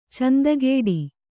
♪ candagēḍi